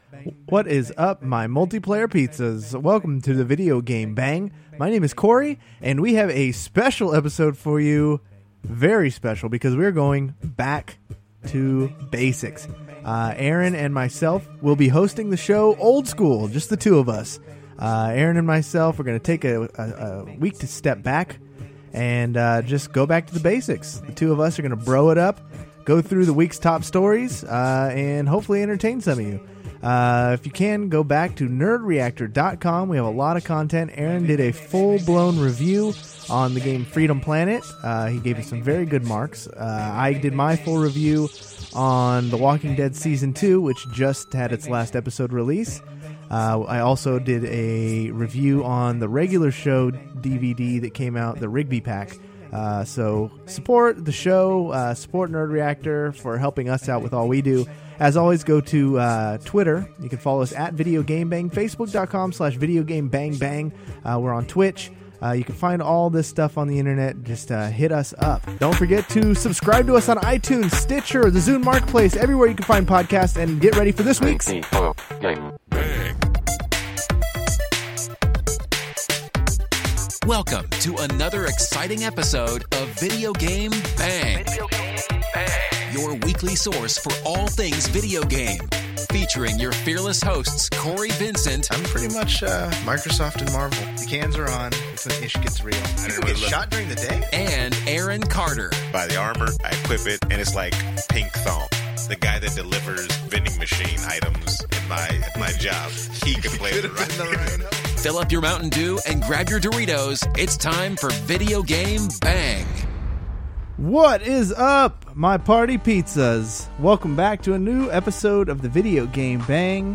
We go from a 4-Player show back to a 2-Player Turbo edition of the podcast just like the old days. We are taking shots at Cyber terrorist group Lizard Squad, and the streamers who played into their game.